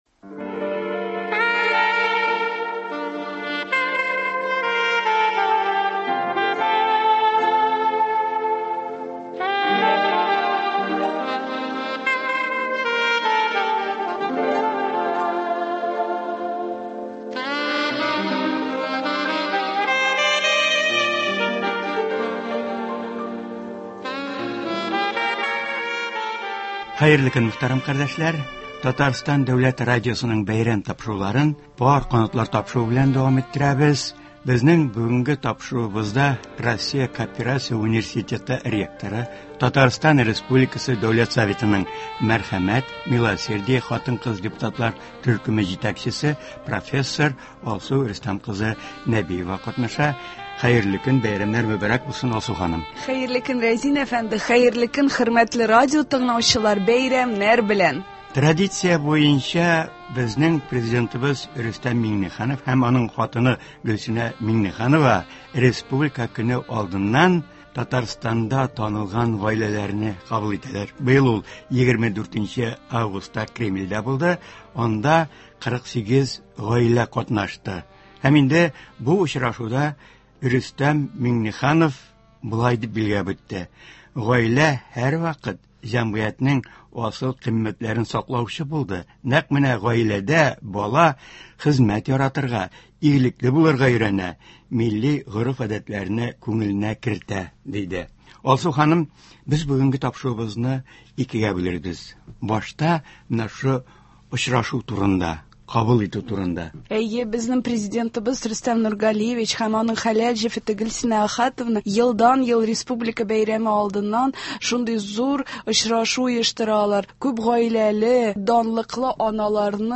Татарстанда да гаилә кыйммәтләрен саклауга, күп балалы гаиләләрне яклауга зур игътибар бирелә. Болар хакында бәйрәм тапшыруында турыдан-туры эфирда Татарстан республикасы Дәүләт Советы депутаты, Мәскәү кооперация университеты ректоры, профессор Алсу Рөстәм кызы Нәбиева сөйләячәк һәм тыңлаучылар сорауларына җавап бирәчәк.